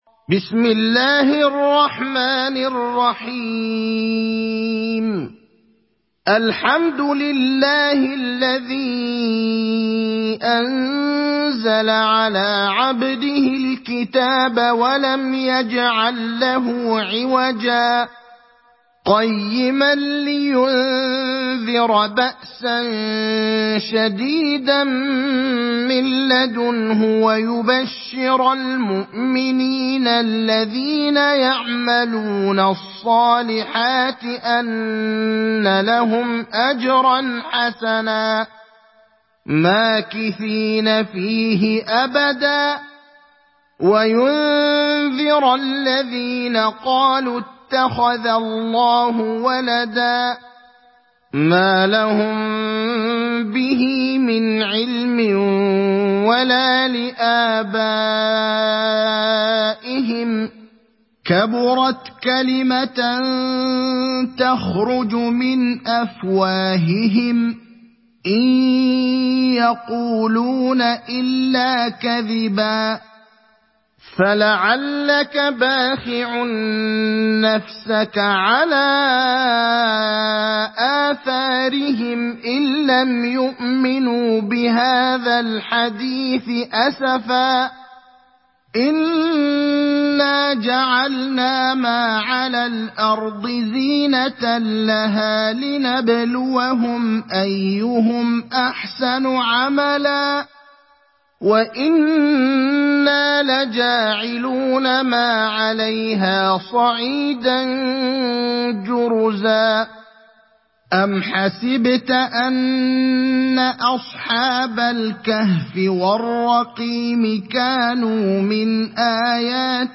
دانلود سوره الكهف mp3 إبراهيم الأخضر (روایت حفص)